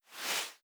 back-button-click.wav